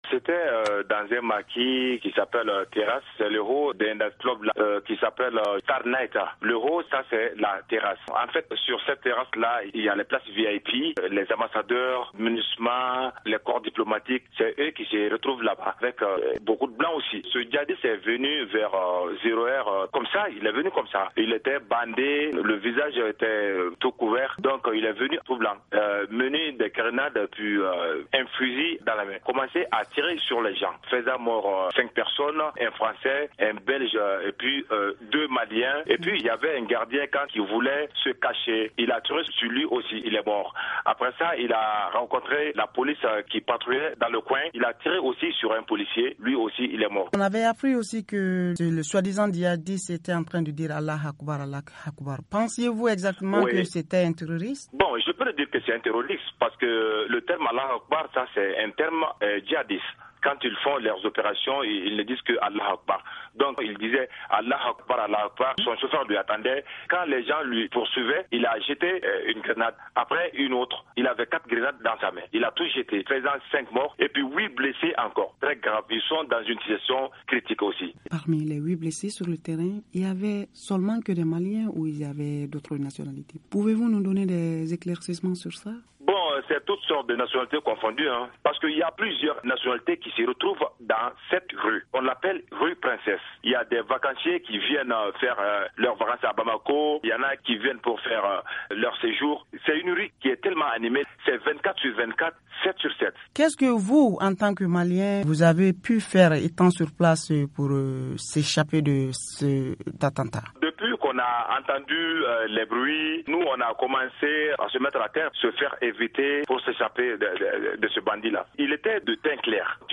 Un Bamakois témoigne